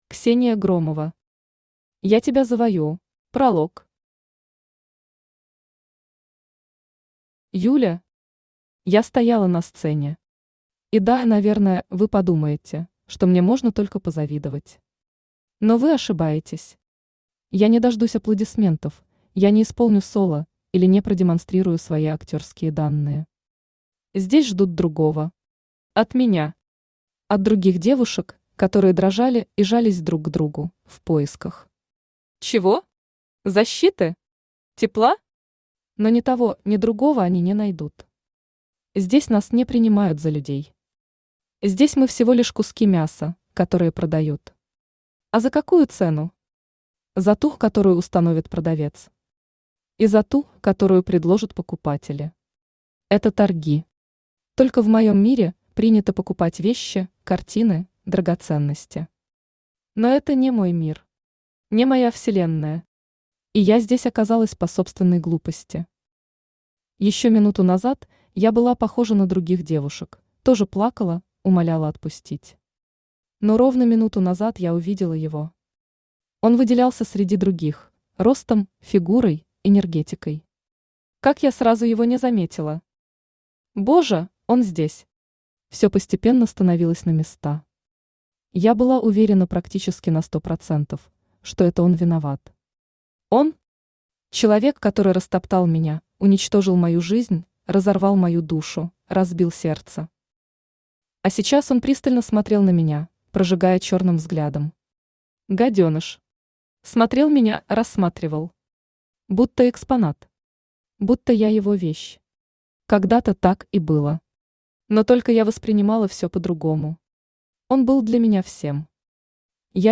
Aудиокнига Я тебя завоюю Автор Ксения Громова Читает аудиокнигу Авточтец ЛитРес.